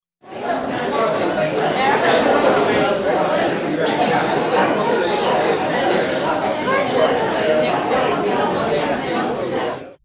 Restaurant Chatter
Restaurant_chatter.mp3